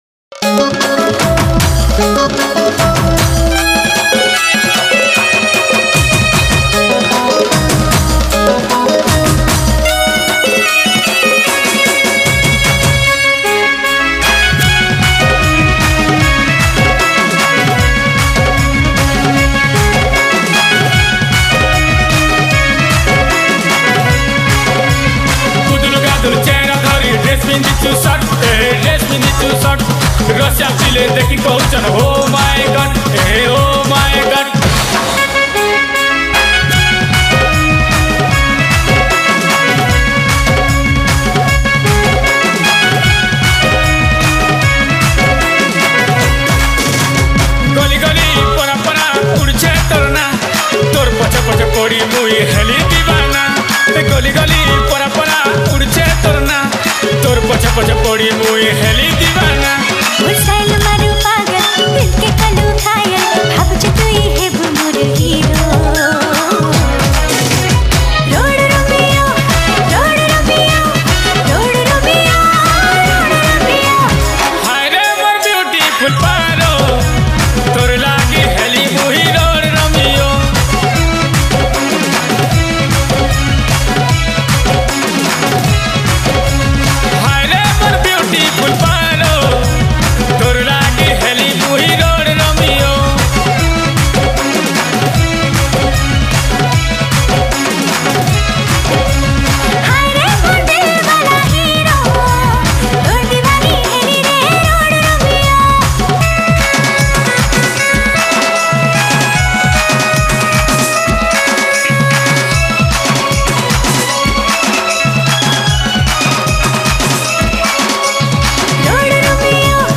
New Sambalpuri Song